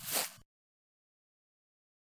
footsteps-single-outdoors-002-06.ogg